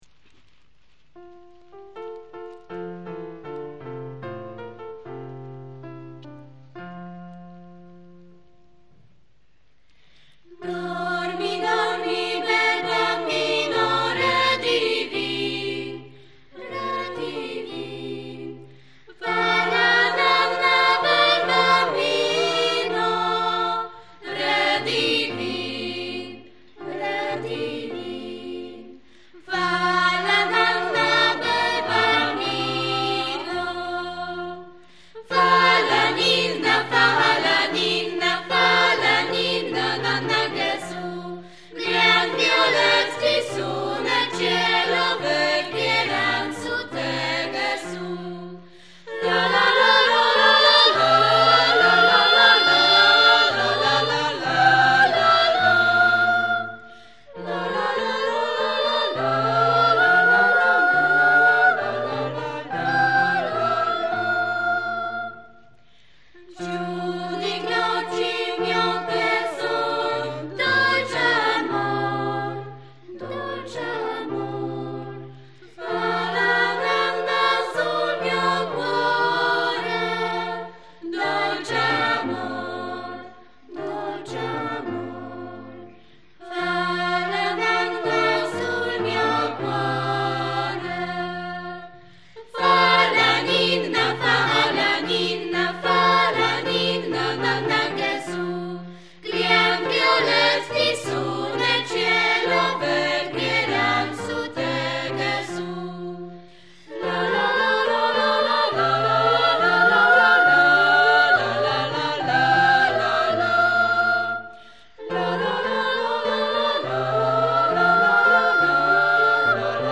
Nagrania live, utwory w formacie mp3 (96kbps),
zarejestrowane na koncertach w Jasieniu i w Domecku.
kolęda włoska